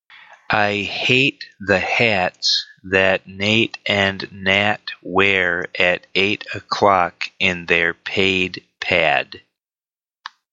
Again, each tongue twister comes with a model recording that can guide your pronunciation.
MODEL RECORDING